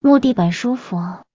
木地板舒服.mp3